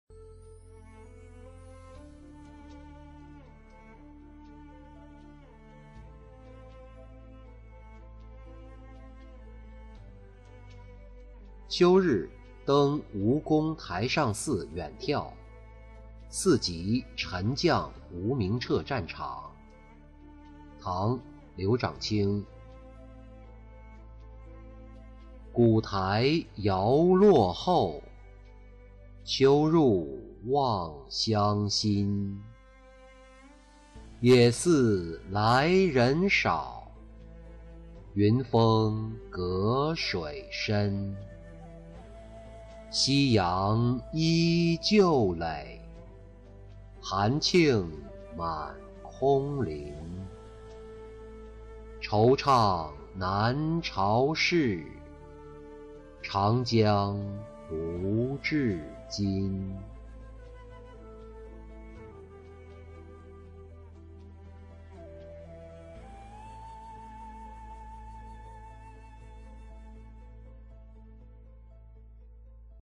秋日登吴公台上寺远眺-音频朗读